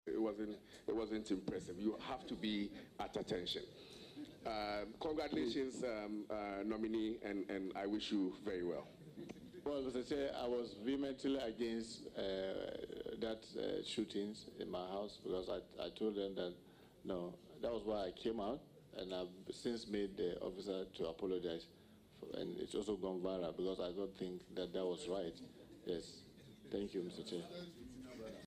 However, appearing before the Appointments Committee of Parliament, Muntaka disclosed he was vehemently against the action.
On a lighter note, the former Minister for Food and Agriculture said the nominee is supposed to be in the position of attention during a gun salute, sparking laughter among the audience.